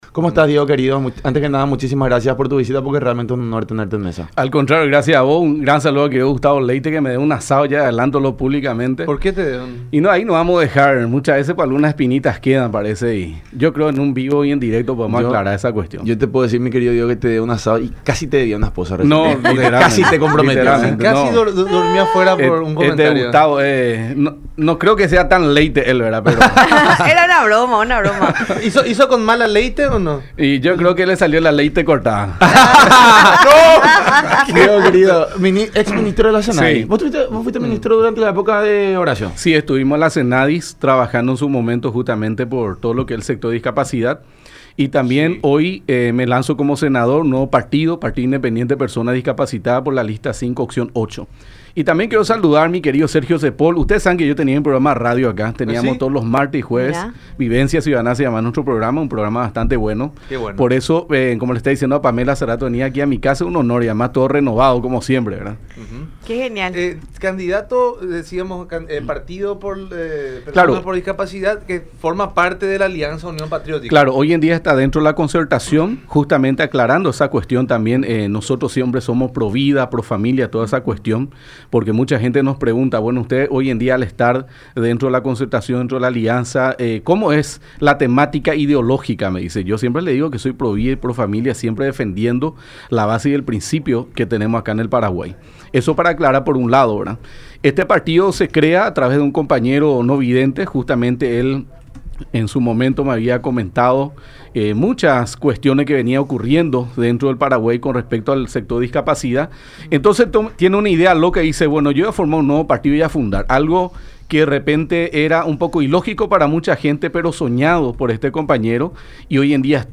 “Me lanzo como senador por el Partido de las Personas Discapacitadas, creado hace poco por unos colegas. Este es un partido nuevo, un partido independiente que está dentro de la Concertación. Nosotros somos provida y profamilia y nos postulamos a senaduría, diputación, concejalía departamental, trabajando por un sector bastante vulnerable y poco visibilizado”, dijo Samaniego en su visita a los estudios de Unión TV y radio La Unión durante el programa La Unión Hace La Fuerza.